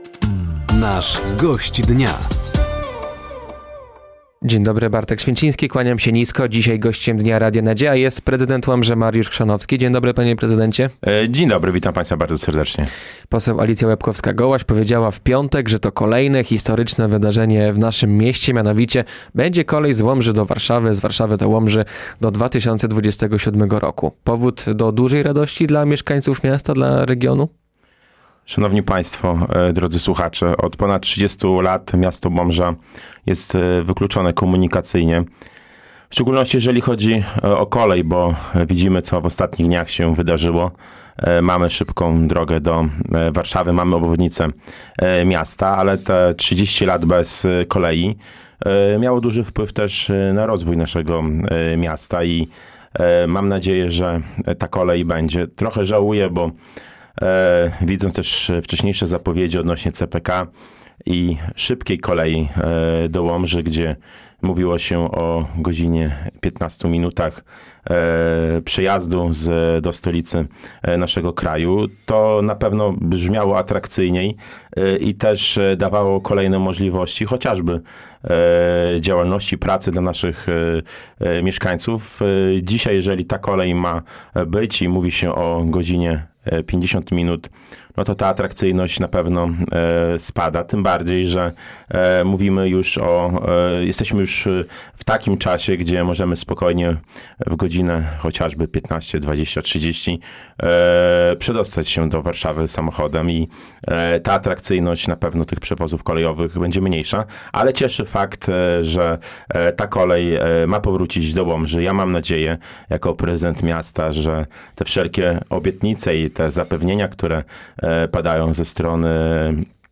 Gościem Dnia Radia Nadzieja był prezydent Łomży Mariusz Chrzanowski. Głównym tematem rozmowy była kolej z Łomży do Warszawy, która ma powstać w 2027 roku oraz obwodnica miasta.